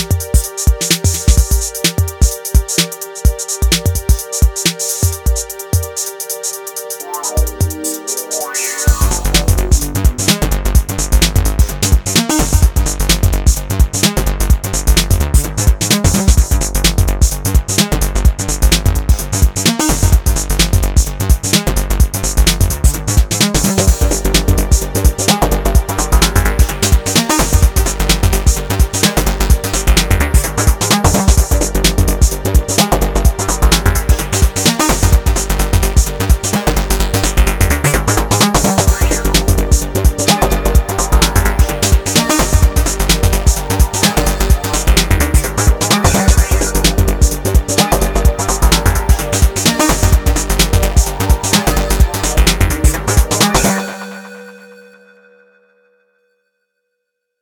Some diesel from the DT2 today featuring drum oneshots + wavetables and single cycle oscillators. No added effects except a limiter